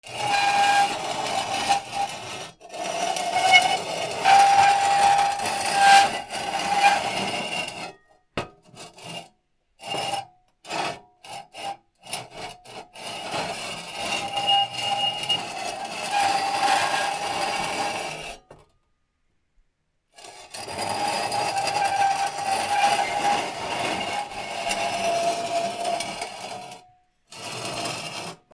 錆びた金属をこすり合わせる
/ M｜他分類 / L01 ｜小道具 / 金属